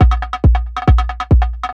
Index of /90_sSampleCDs/Best Service ProSamples vol.45 - Techno ID [AIFF, EXS24, HALion, WAV] 1CD/PS-45 AIFF Techno ID/PS-45 AIF loops/AIF drum-loops/AIF main-version